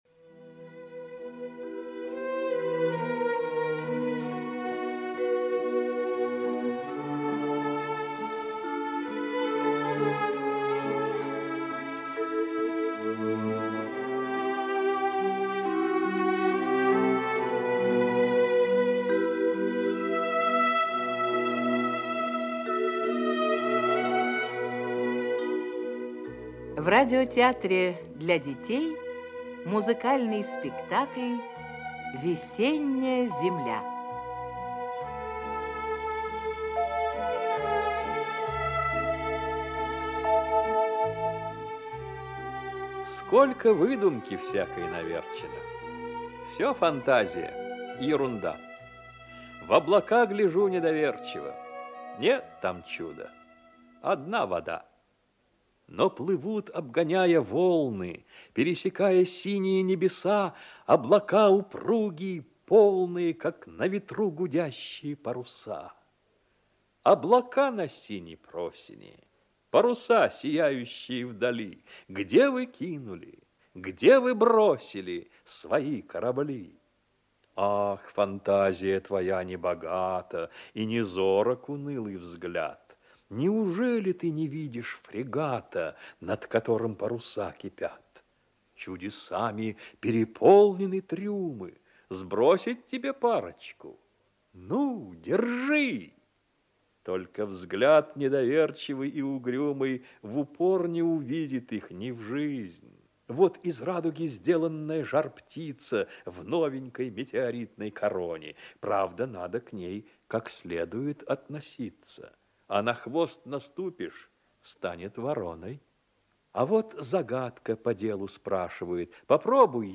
Радиопостановка по повести "Книжка про Гришку". Мальчика Гришку на лето отправили к дяде за город.